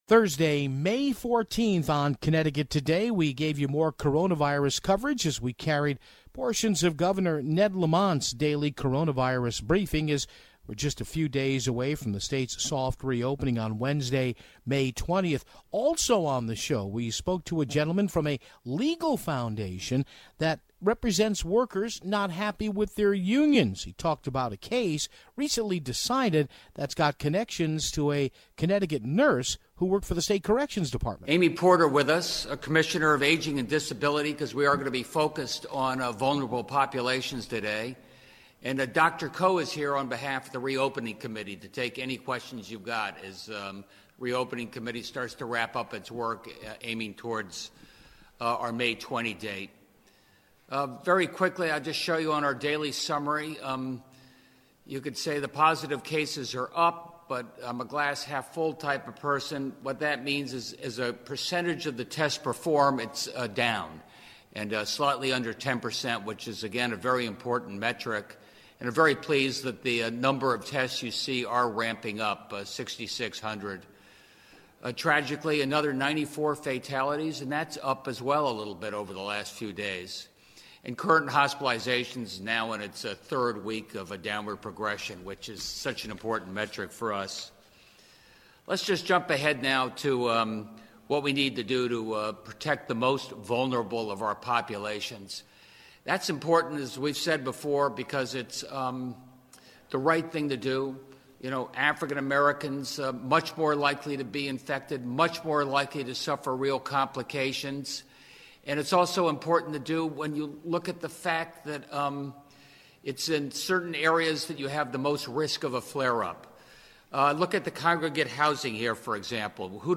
As we draw nearer to the soft re-opening of the state, we feature portions of Governor Lamont's daily press update.